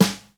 56BRUSHSD1-R.wav